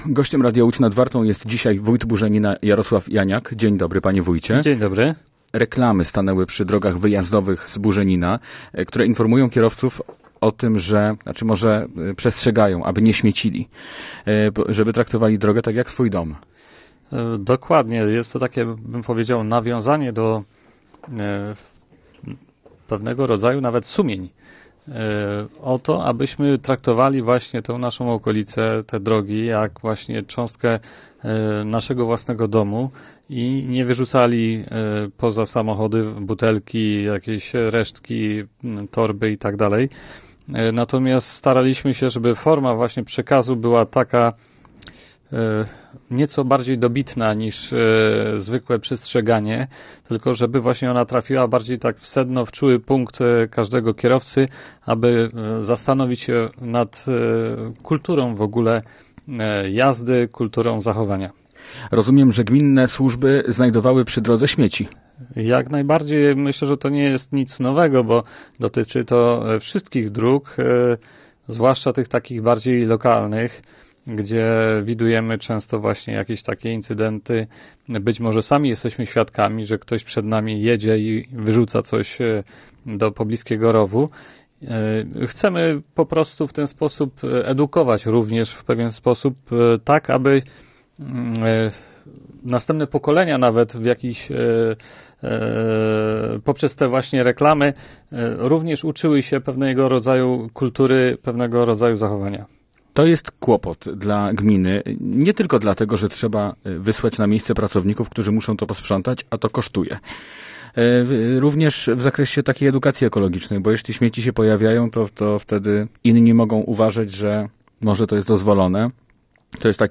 Gościem Radia Łódź Nad Wartą był wójt Burzenina, Jarosław Janiak.